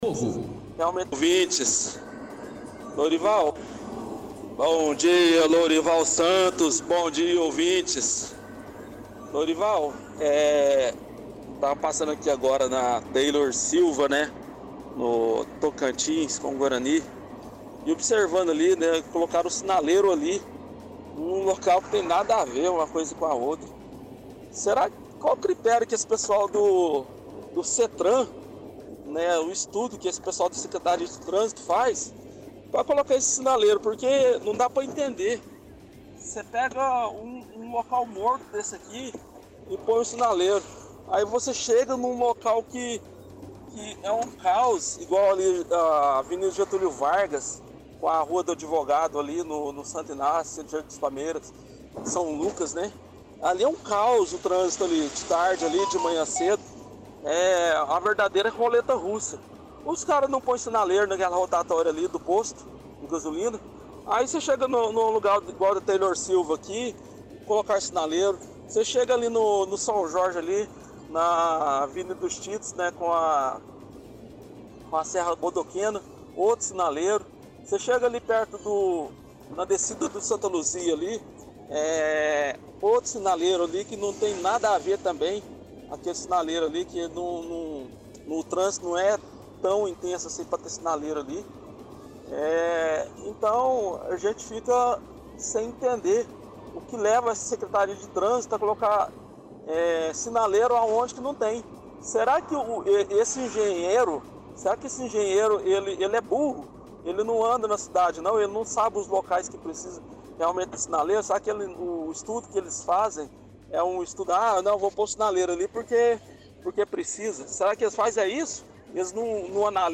– Ouvinte fala que colocaram um semáforo no bairro do Tocantins.